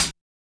hihat01.wav